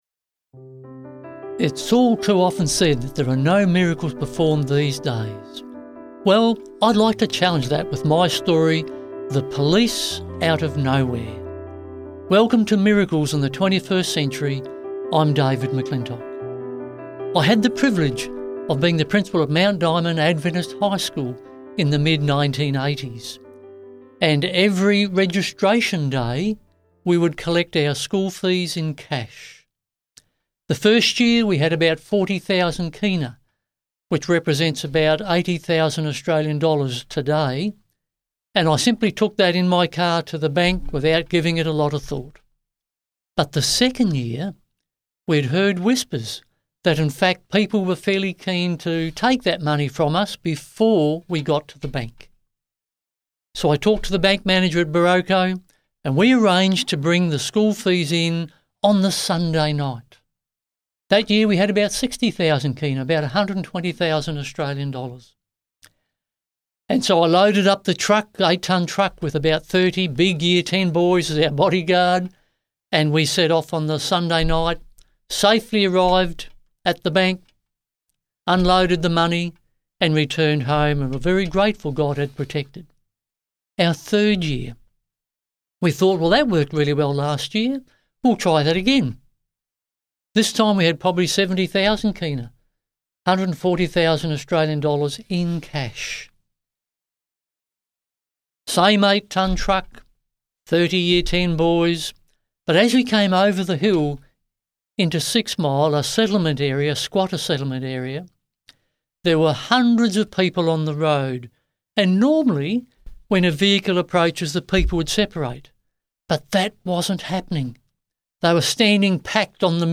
Can God send angels to protect you? This incredible miracle story & Christian testimony reveals God's divine protection in real life — police appeared out of nowhere to save the day.
Music Credits: